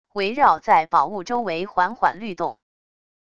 围绕在宝物周围缓缓律动wav音频